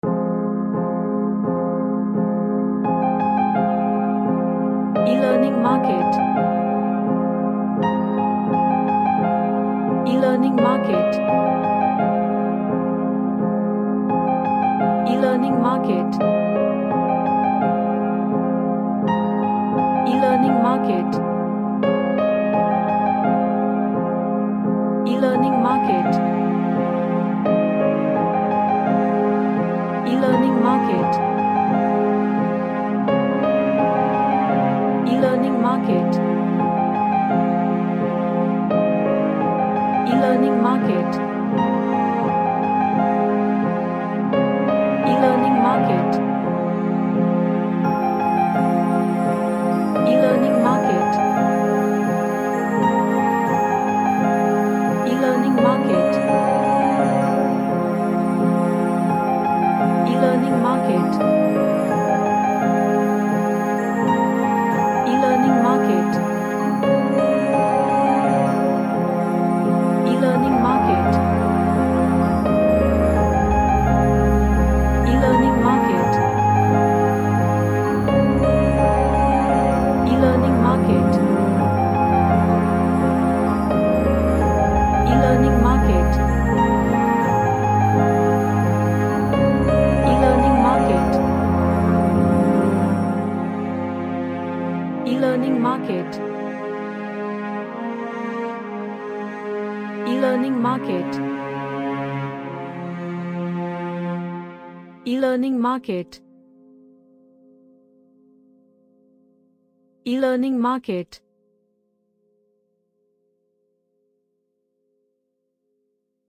A sad cinematic track
Sad / NostalgicEmotional